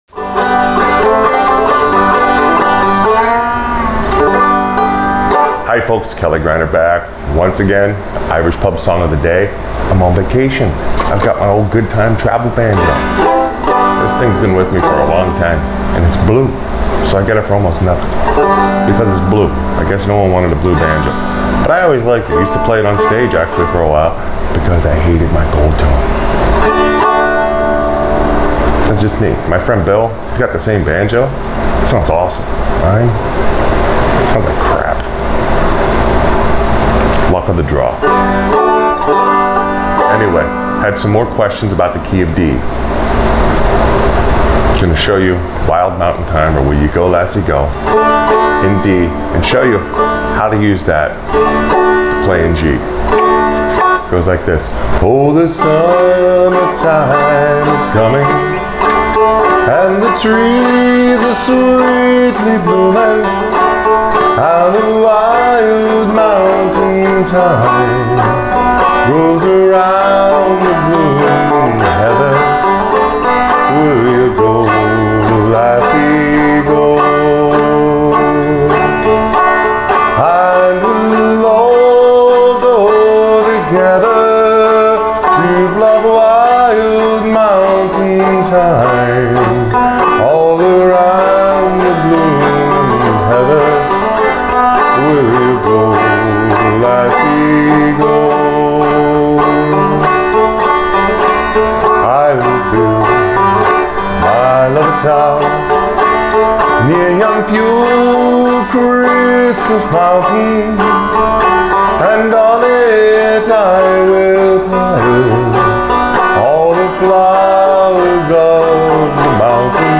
Clawhammer BanjoFrailing BanjoInstructionIrish Pub Song Of The Day